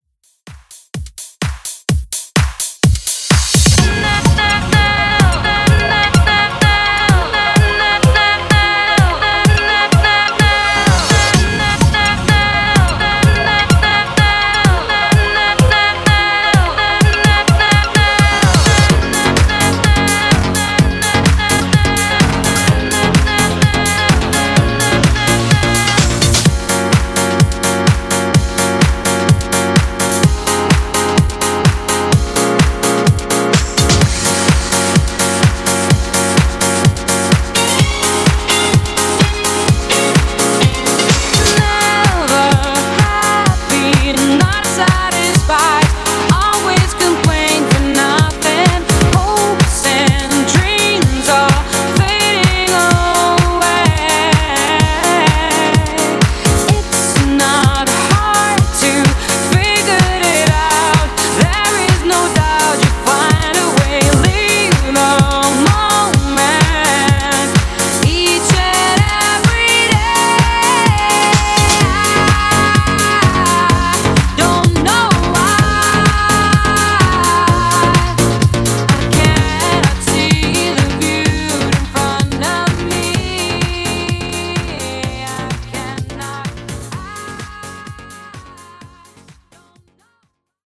Dance Re-Drum)Date Added